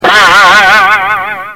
Boing
Boing.mp3